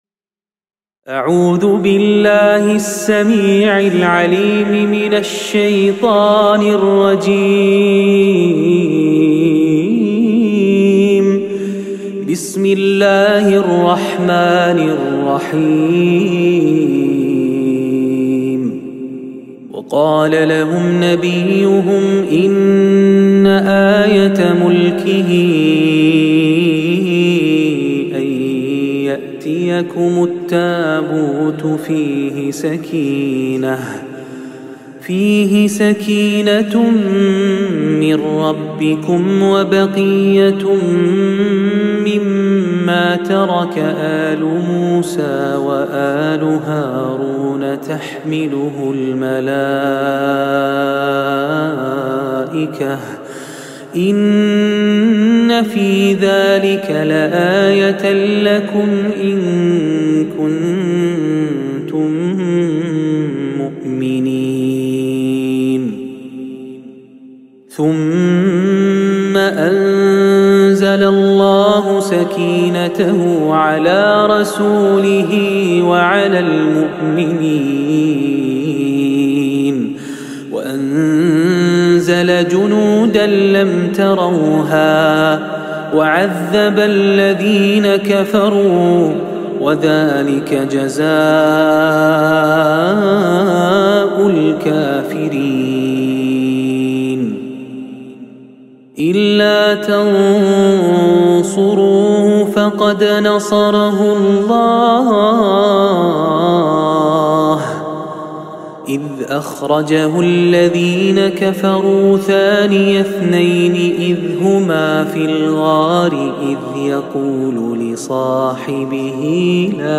অনিদ্রা ও ডিপ্রেশন দূর করার রুক‌ইয়াহ